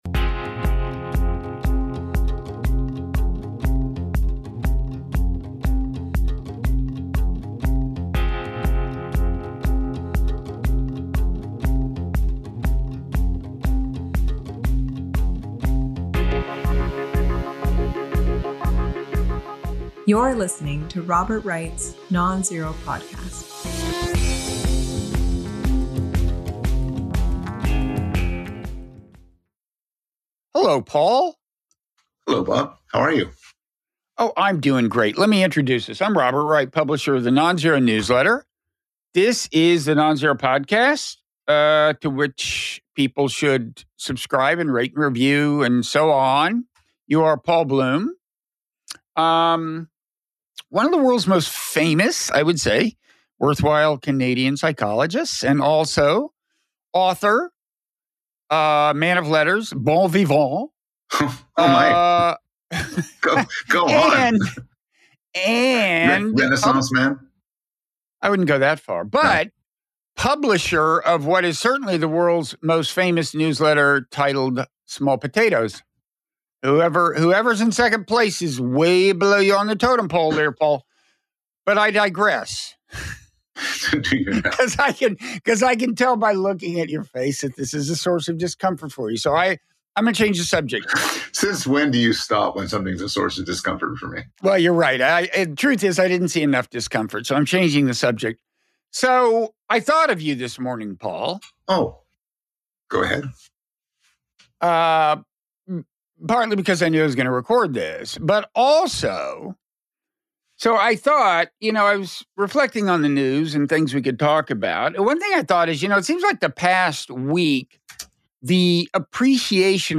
Trump’s Next-Level Corruption (Robert Wright & Paul Bloom) (Robert Wright interviews Paul Bloom; 30 May 2025) | Padverb